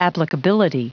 Prononciation du mot applicability en anglais (fichier audio)
Prononciation du mot : applicability